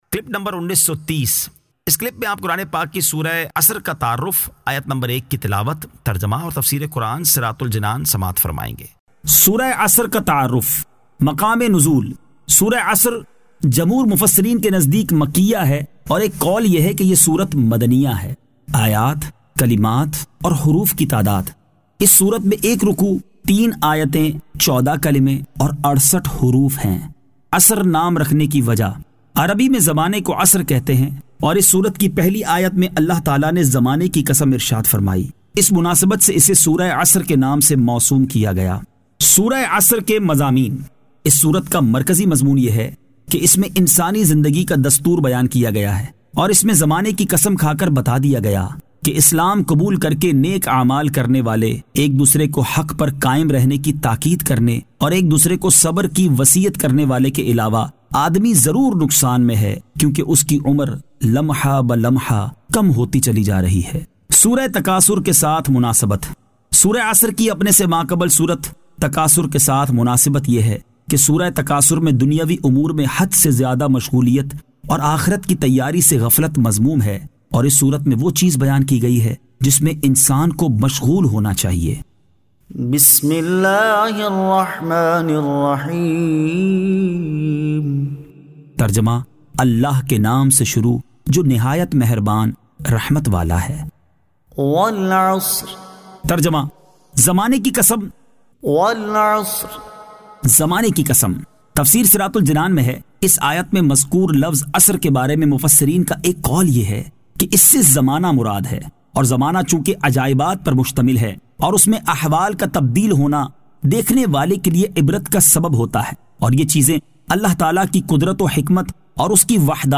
Surah Al-Asr 01 To 01 Tilawat , Tarjama , Tafseer